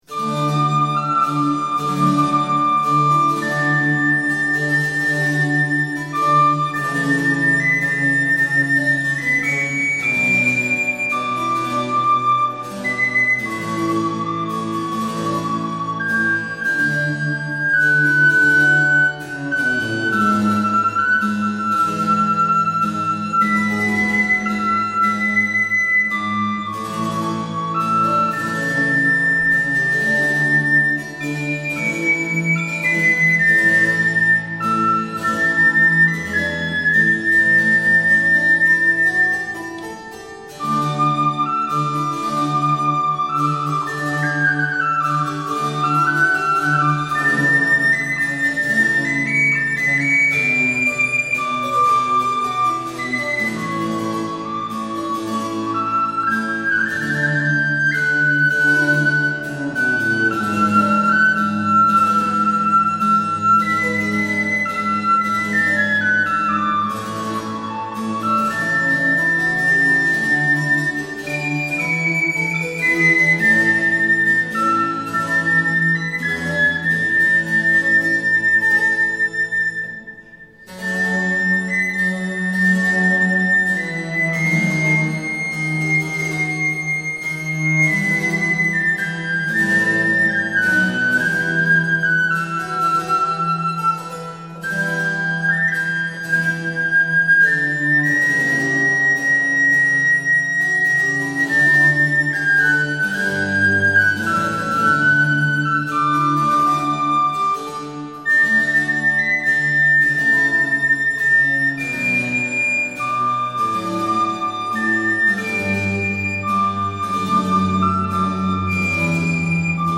harpsichord
Largo